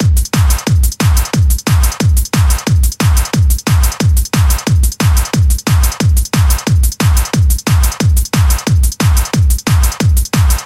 简单低音循环
描述：这是我用FL Studio制作的一个简单的低音循环。
标签： 180 bpm Dance Loops Bass Loops 459.55 KB wav Key : Unknown
声道立体声